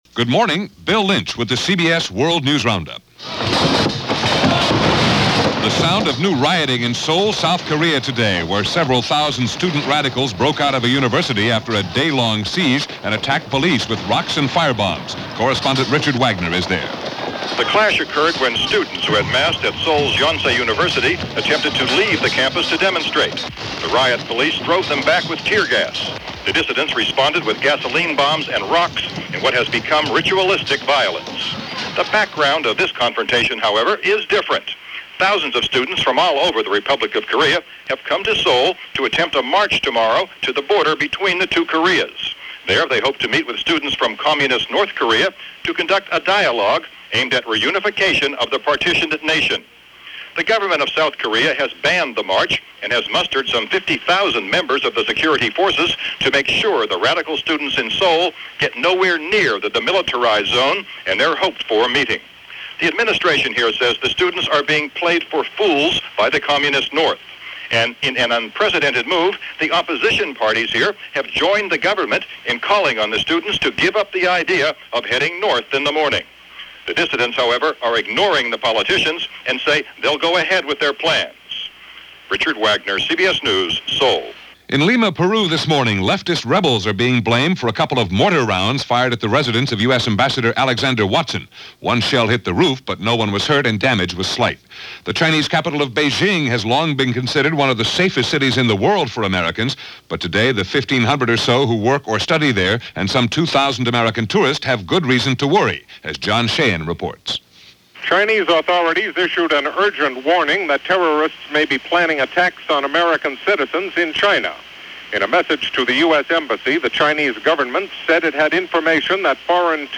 And as the riots dragged on in Seoul, that’s a sample of what went on this rather frenetic June 9th in 1988 as reported on The CBS World News Roundup.